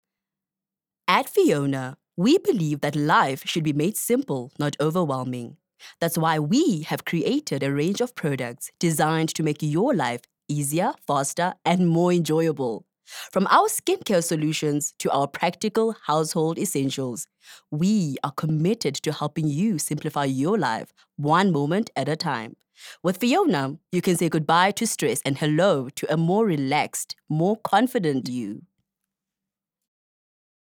confident, conversational, Formal, friendly, informative, sharp, youthful